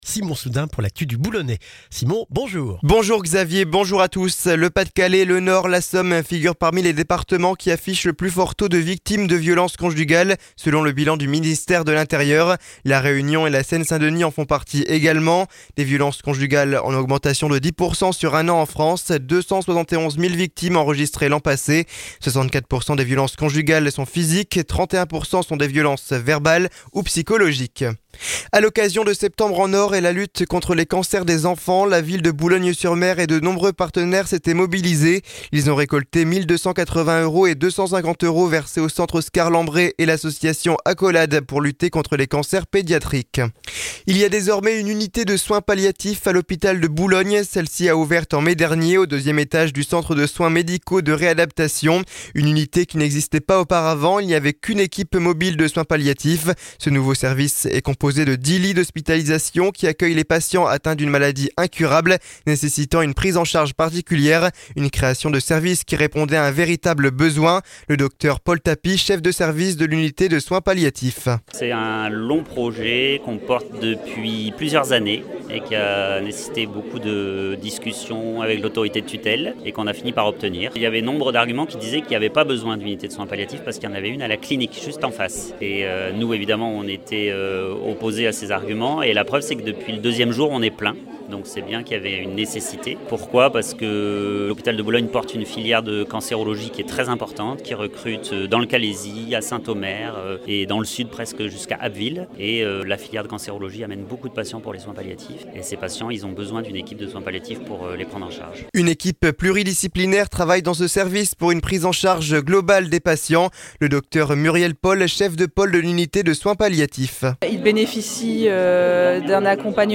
Le journal du jeudi 7 novembre dans le Boulonnais